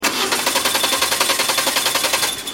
دانلود آهنگ ماشین 12 از افکت صوتی حمل و نقل
دانلود صدای ماشین 12 از ساعد نیوز با لینک مستقیم و کیفیت بالا
جلوه های صوتی